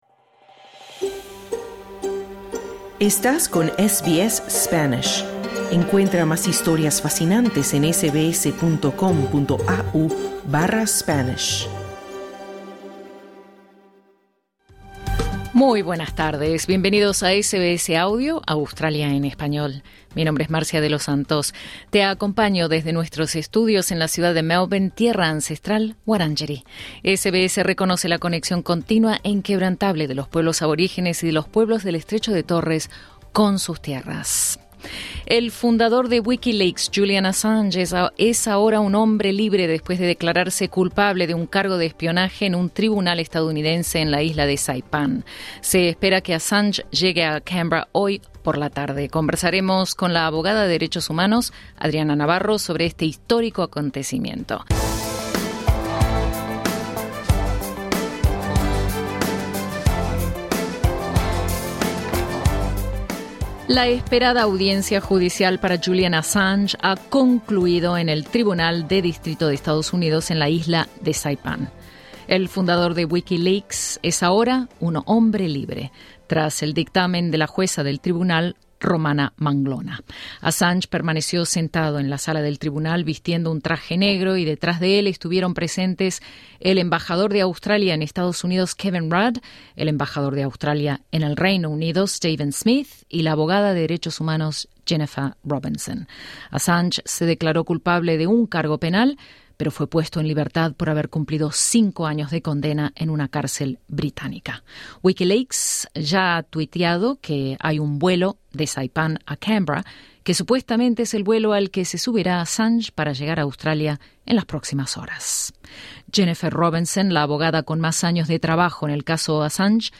Conversamos con la abogada de derechos humanos